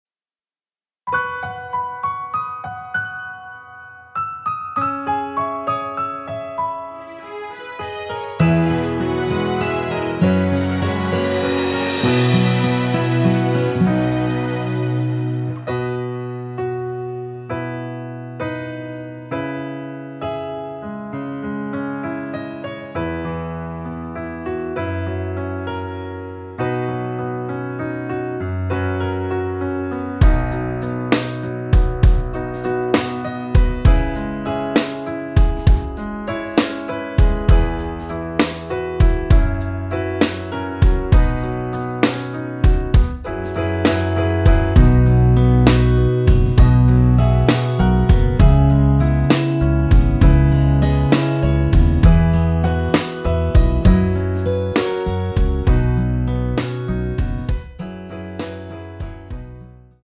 앞부분30초, 뒷부분30초씩 편집해서 올려 드리고 있습니다.
중간에 음이 끈어지고 다시 나오는 이유는
곡명 옆 (-1)은 반음 내림, (+1)은 반음 올림 입니다.